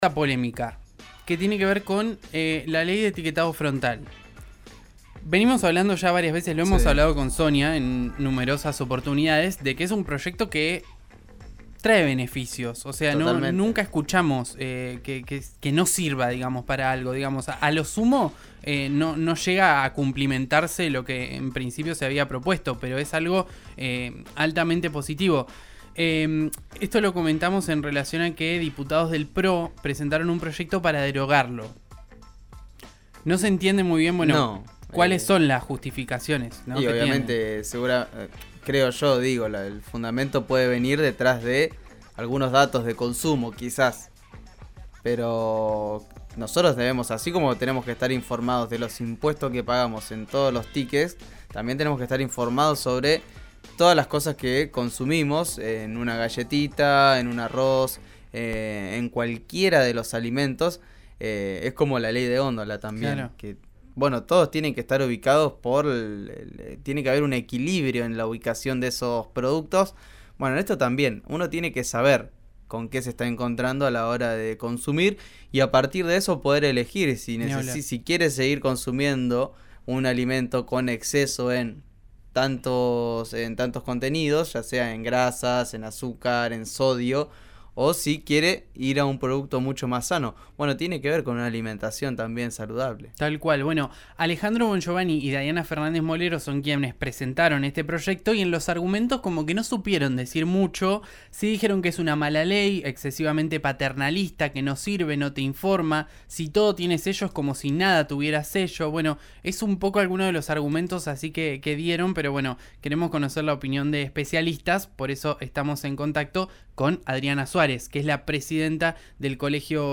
Desde el Colegio de Graduados en Nutrición de Río Negro rechazaron el proyecto de derogación. Escuchá la entrevista de RÍO NEGRO RADIO.